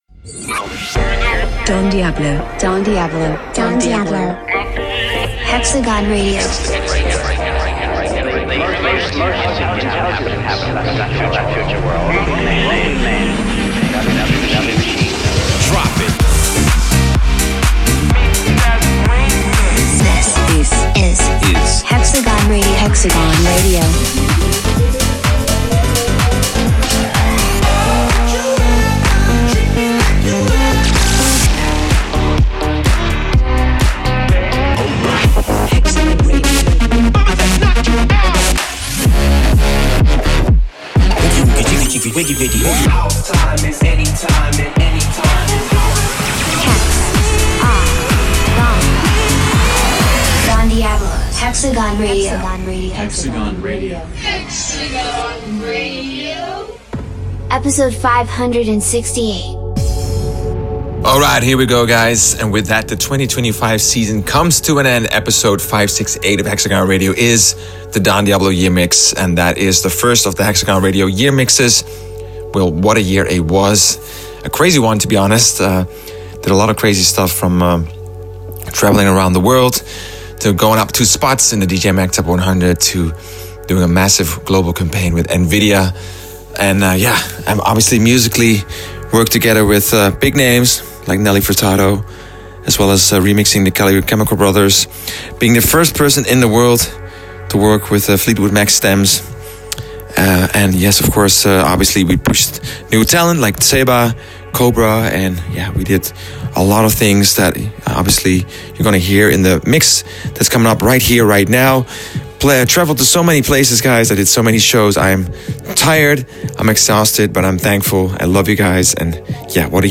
Also find other EDM Livesets, Yearmixes DJ Mixes